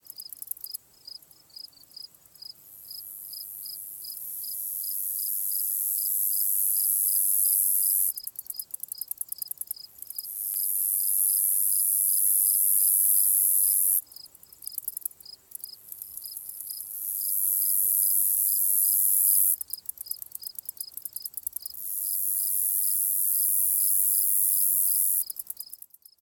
insectday_13.ogg